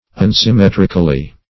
Unsymmetrically \Un`sym*met"ric*al*ly\, adv.